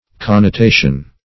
Connotation \Con`no*ta"tion\ (k[o^]n`n[-o]*t[=a]"sh[u^]n), n.